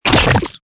CHQ_FACT_paint_splash.mp3